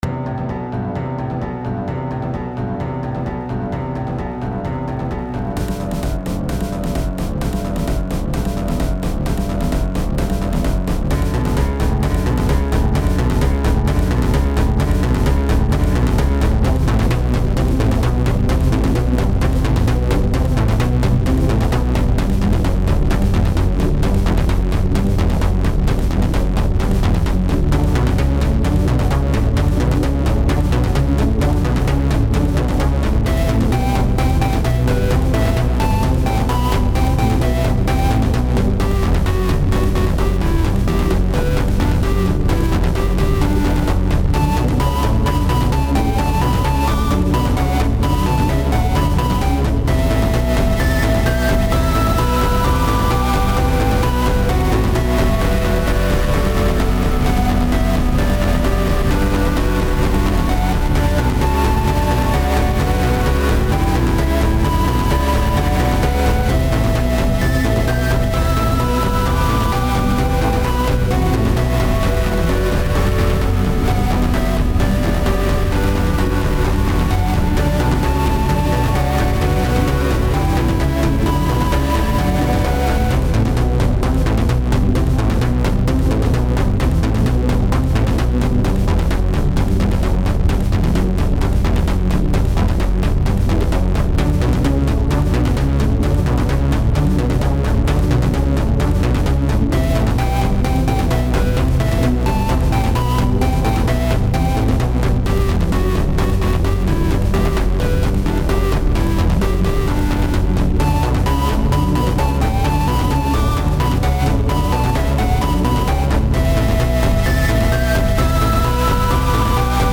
Fading Sigh - actiony/electronic
This is an actiony electronic song.